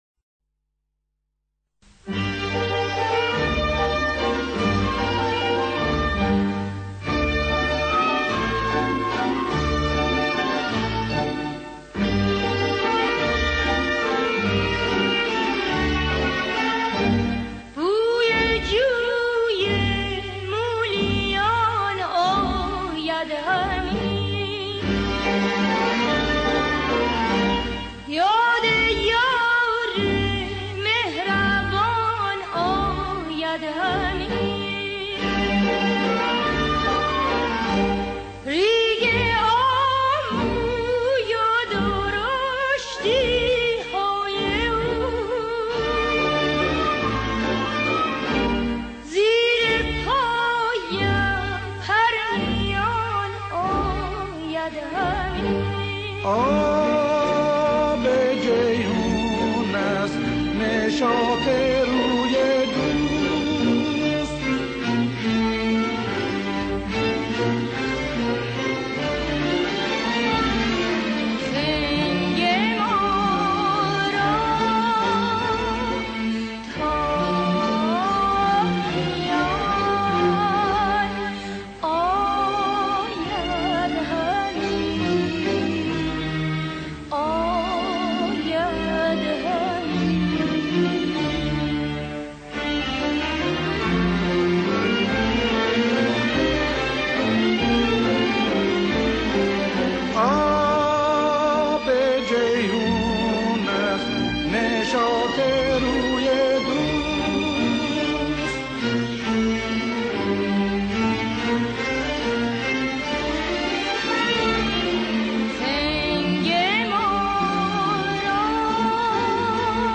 دستگاه: بيات اصفهان